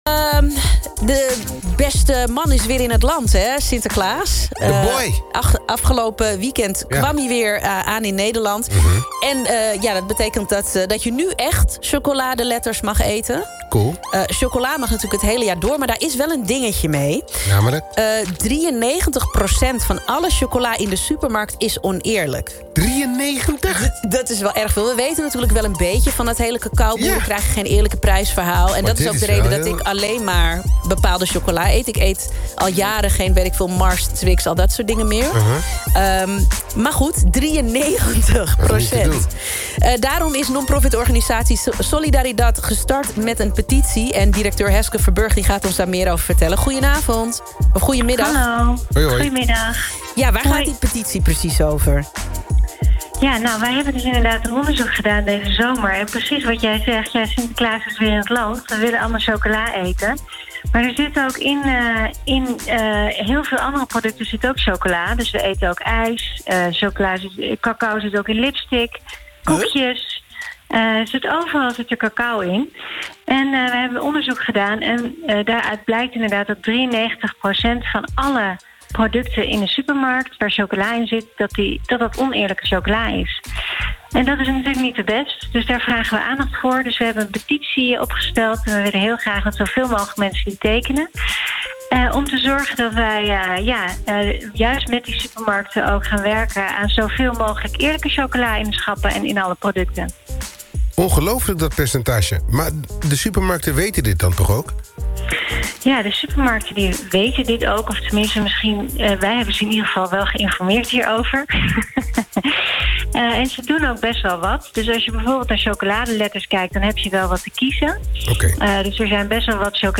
Fragment van ‘Bridge The Gap’ op NPO Blend, woensdag 20 november 17:30 (5 min)